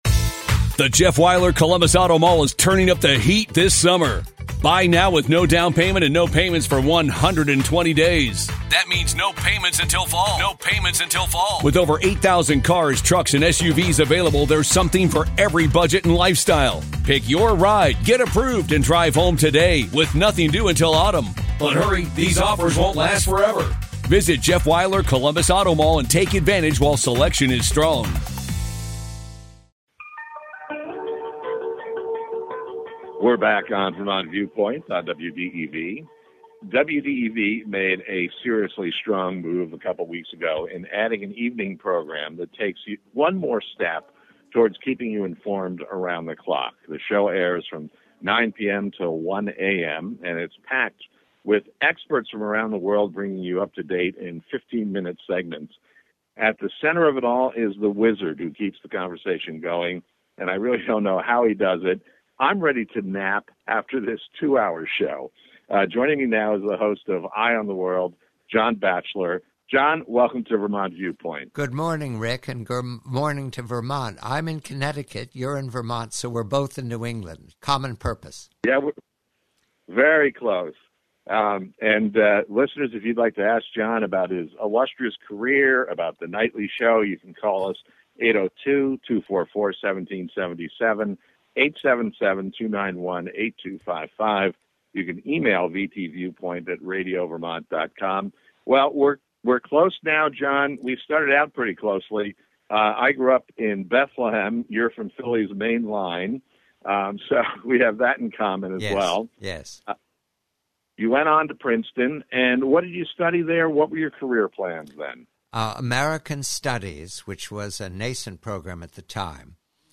Happy conversation